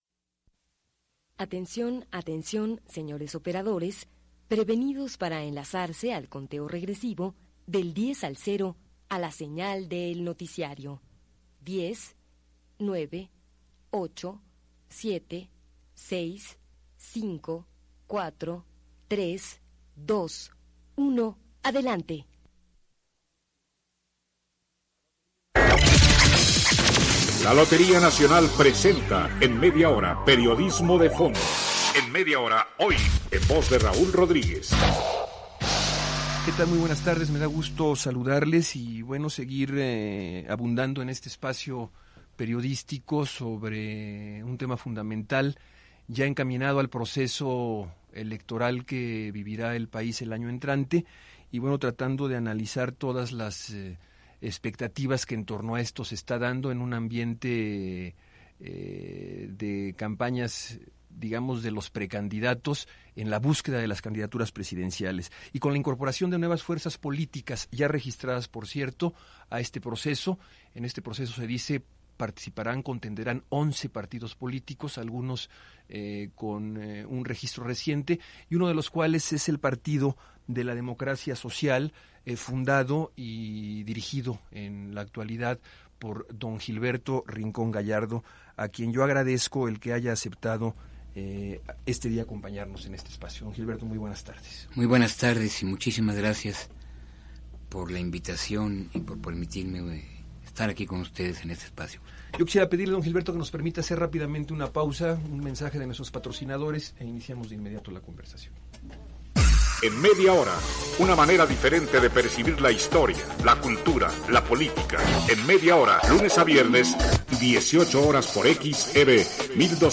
en el programa periodístico “En media hora”, transmitido en 2000.